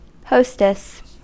Ääntäminen
IPA: [ɔ.bɛʁ.ʒist]